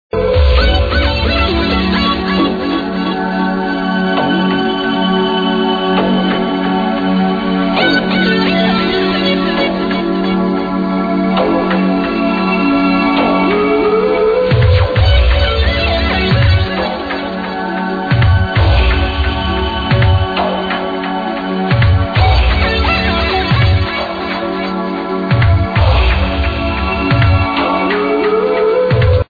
Thumbs up Nice new trance track
live